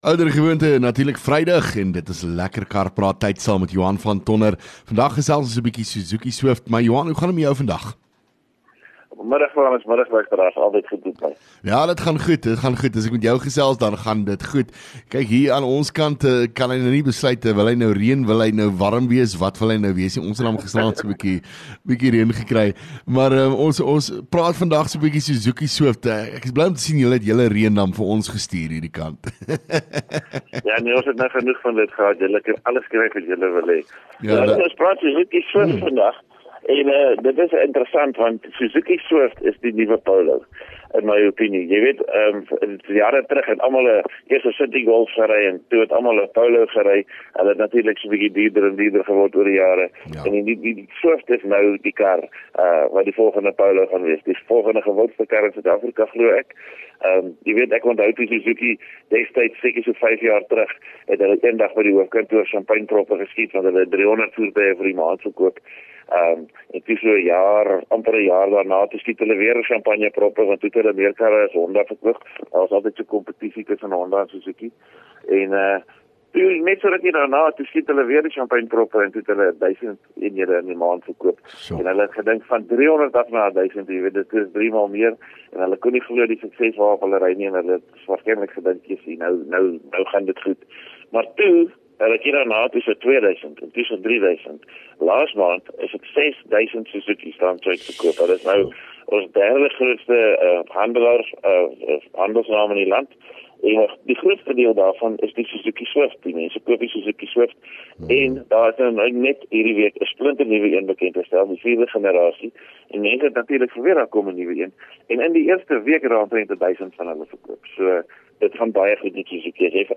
LEKKER FM | Onderhoude 8 Nov LEKKER Kar Praat